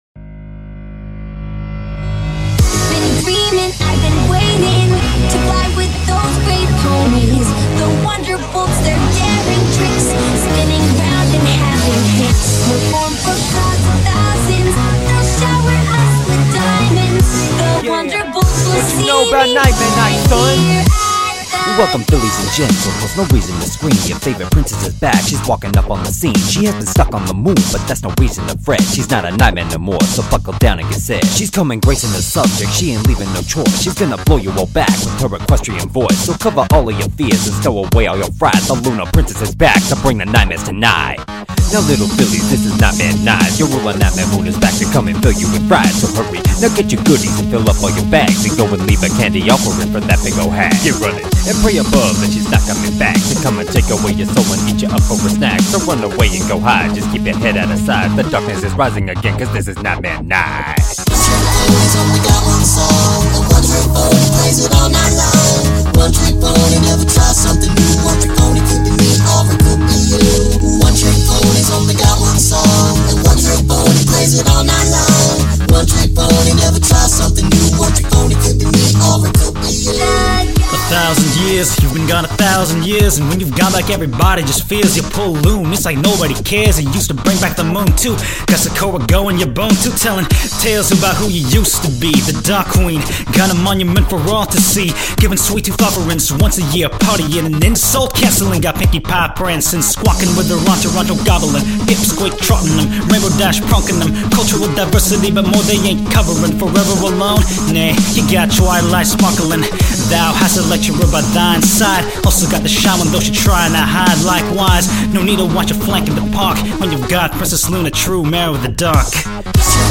Hello and welcome, to a Mashup!